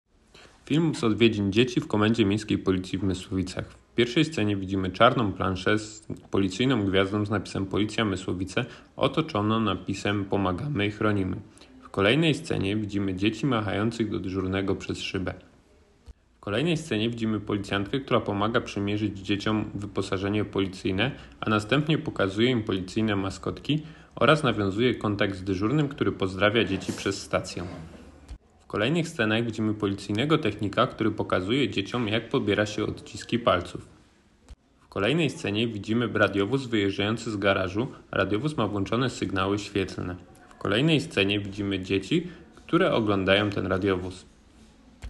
Nagranie audio audiodeskrypcja